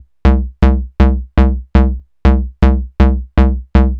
TSNRG2 Off Bass 003.wav